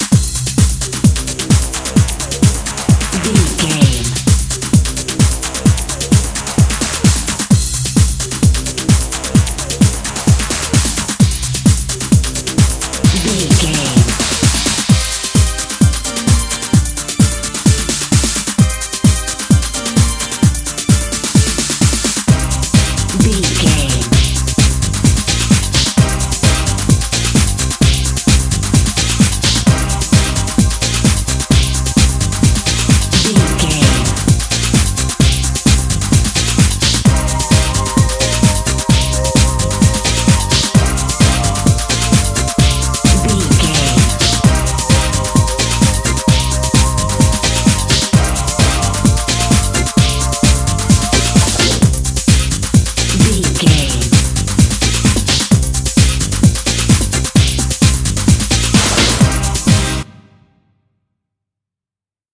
Aeolian/Minor
Fast
drum machine
synthesiser
electric piano
bass guitar
conga